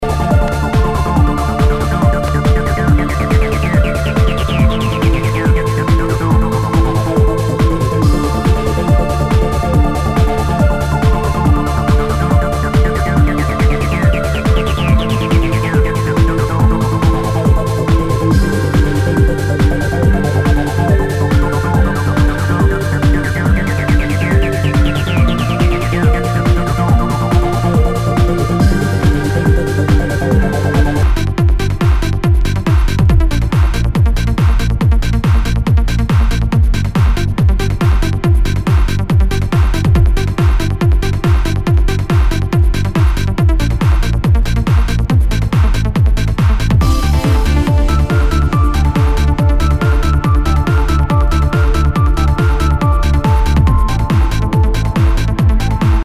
HOUSE/TECHNO/ELECTRO
ナイス！トランス！